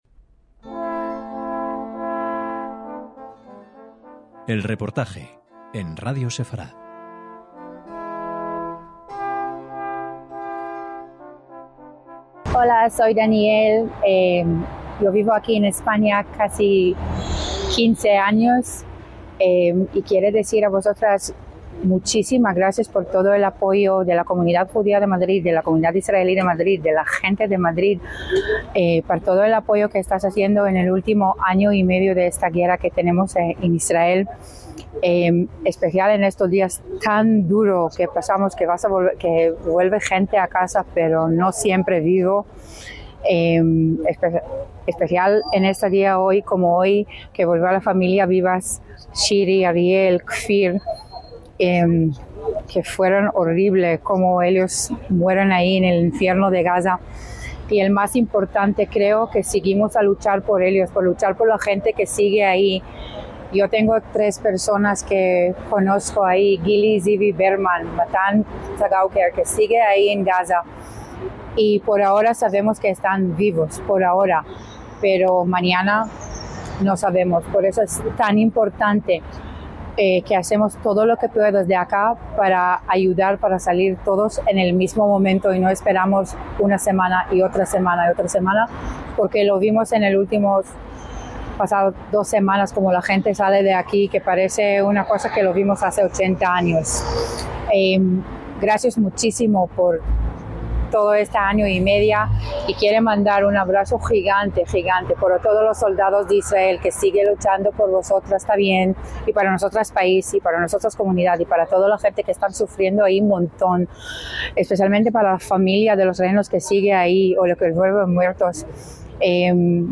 EL REPORTAJE - Ante el dolor, unión y solidaridad. Madres Judías por la Paz continúa con su esfuerzo de hasbará y defensa del derecho de Israel a defenderse. Ante el silencio de buena parte del mundo, en esta concentración en Madrid recordamos especialmente a Shiri Bibas y sus pequeños Ariel y Kfir Z''L.